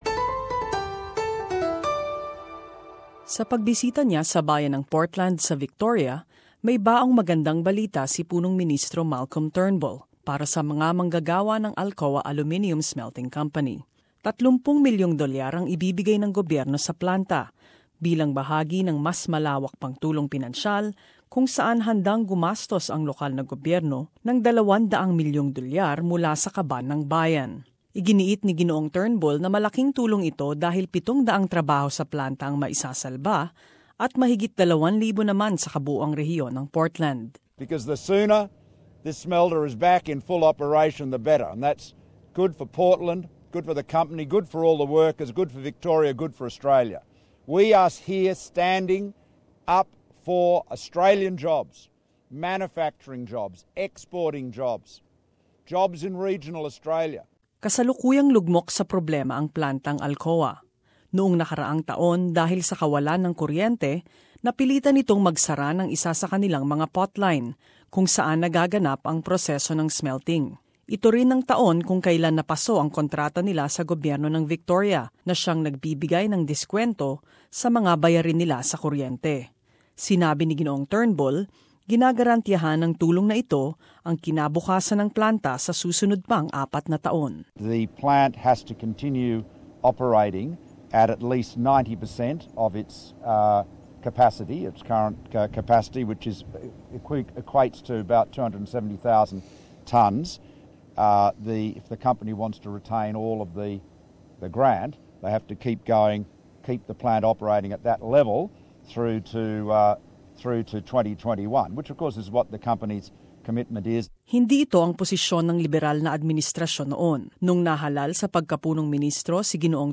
In this report, one industry group says the government shouldn't hand out money to businesses unless it's as a last resort.